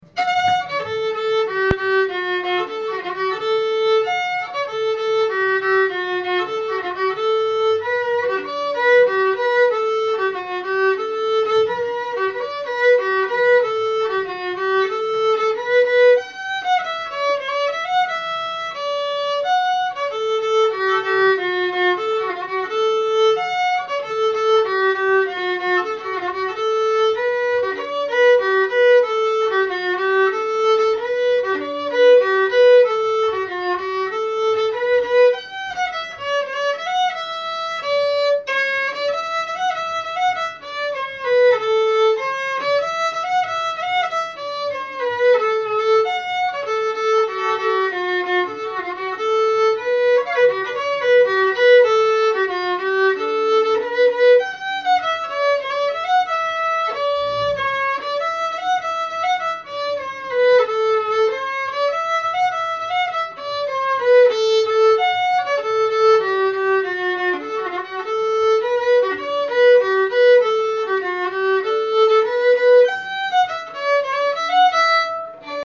Kadrilj och folkdans